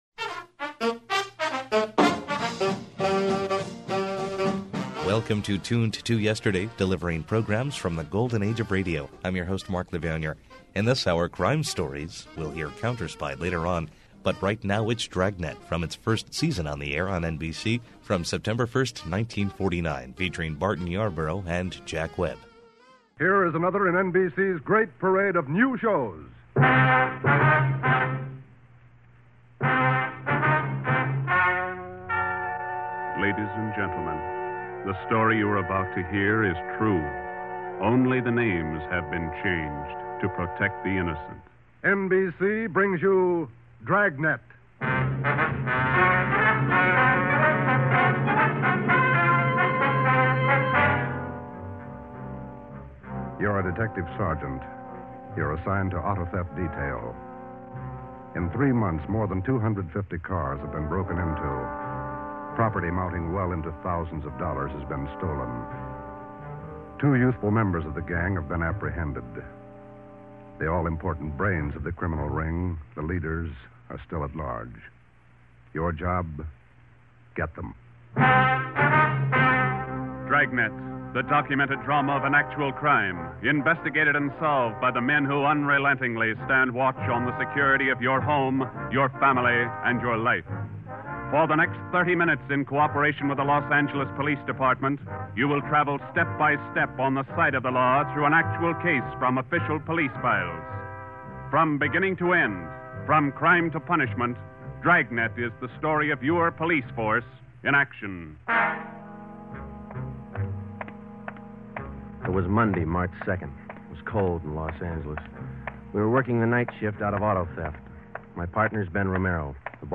Decades ago, WRVO Public Media began broadcasting old-time radio with a small collection comprising 20 reel-to-reel tapes.
The highest quality broadcasts are restored and played as they were heard years and years ago.
Audio Drama